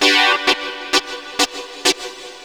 SYNTHLOOP2-L.wav